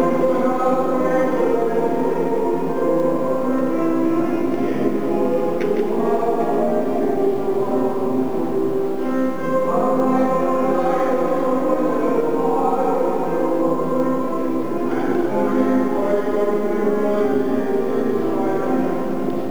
合唱が行われており、なぜかビデオのスイッチが入っていましたので（かなりウソっぽい）、音のみお聞き下さい。
stgiles.wav